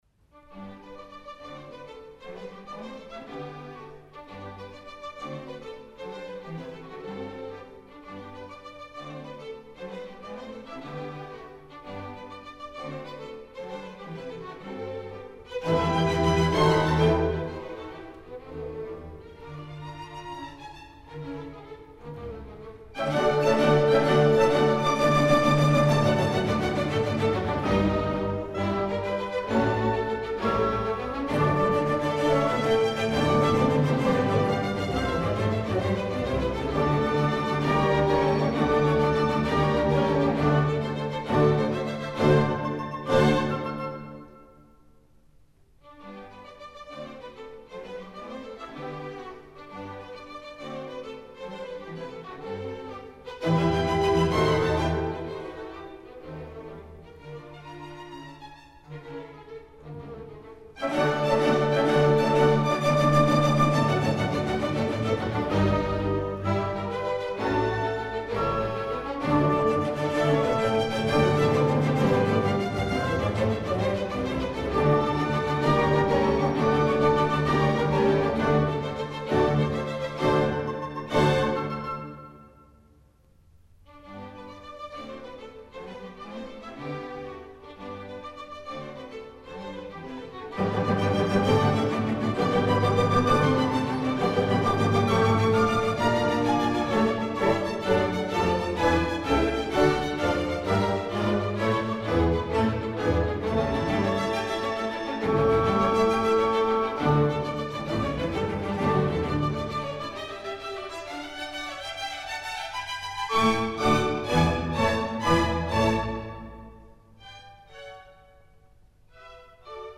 The end of the last movement sees the reappearance of the kettledrums and military percussion, tying the work together.
The final movement is a rondo, and the recurring main theme became widely popular in England, where it would appear as a dance theme in ballrooms around the country, anxious to reflect what was happening in London.
This recording was made in 1960, with Bernhard Paumgartner leading the Camerata Academica of the Salzburg Mozarteum.
Bernhard Paumgartner
Camerata Academica du Mozarteum de Salzbourg